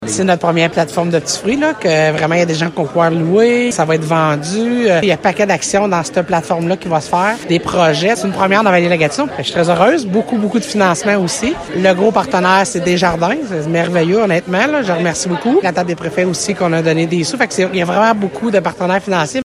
L’objectif du projet est de louer ces parcelles de terre et leurs équipements à des entrepreneurs locaux. La préfète de la Vallée-de-la-Gatineau, Chantal Lamarche, qui est impliquée dans le projet depuis le tout début en 2018, se dit très fière :